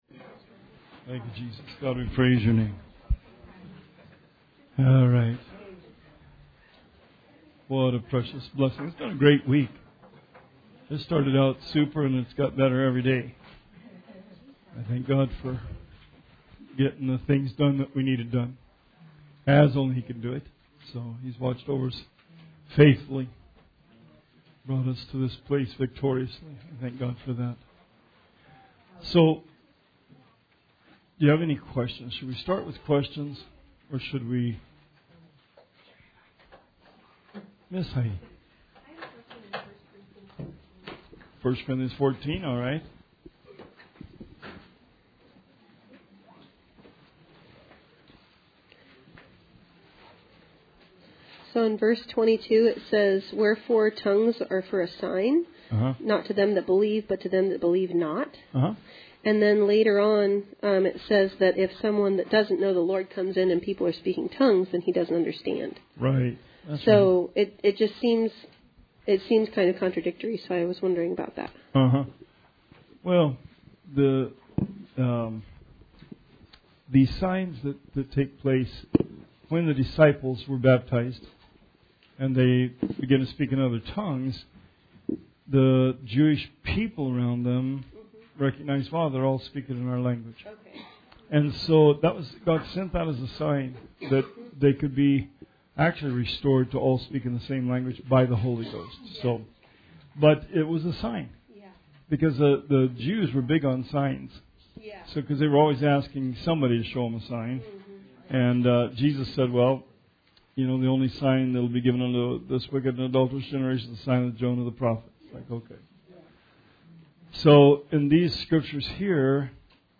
Bible Study 1/31/18